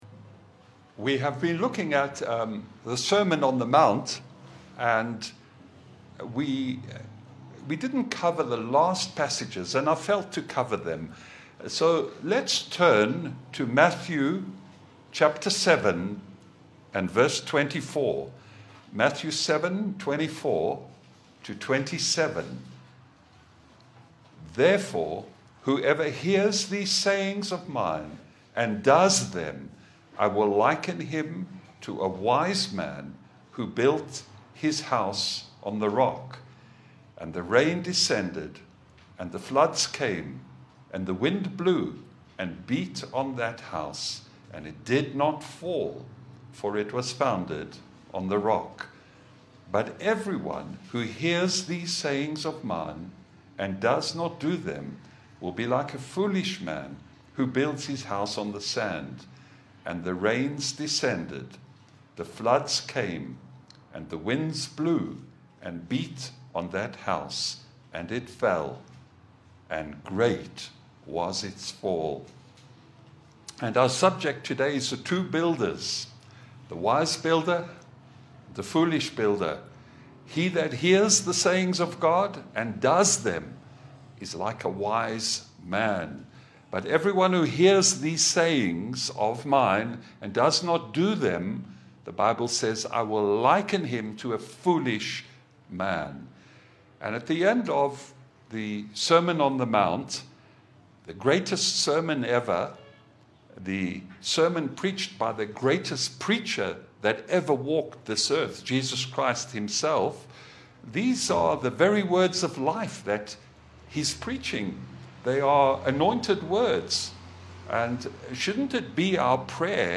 A clear and heartfelt message on Jesus’ parable of the wise and foolish builders—showing why hearing and doing God’s Word is the only solid foundation for life, storms, and eternity.
Passage: Matthew 7:24 Service Type: Sunday Bible fellowship